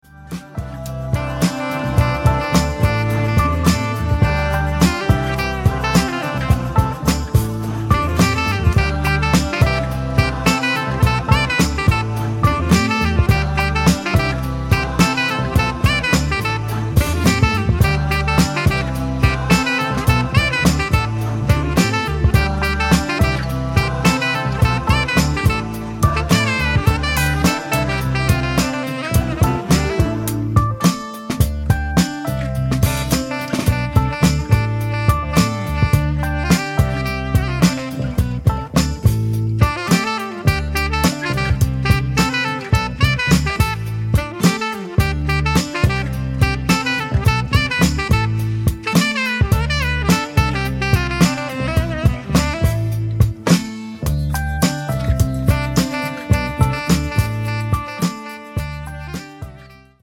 exclusive Dub cut